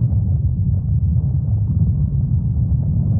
tt_s_ara_cmg_groundquake.ogg